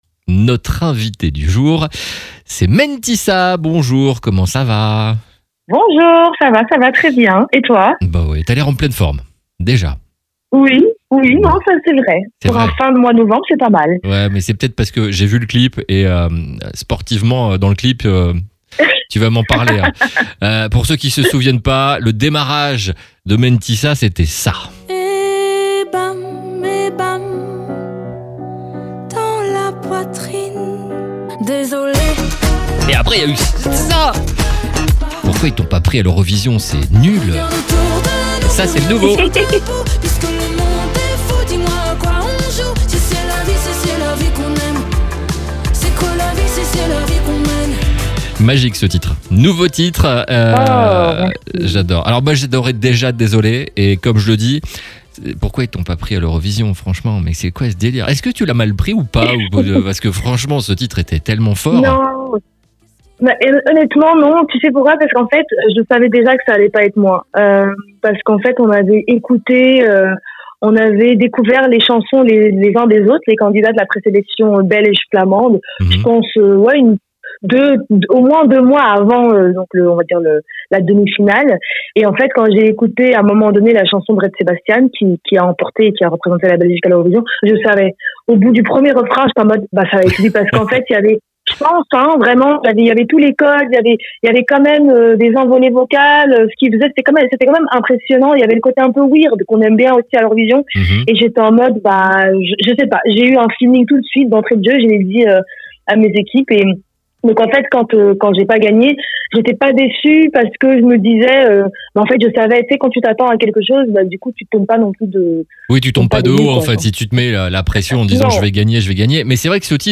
Les interviews exclusifs de RCB Radio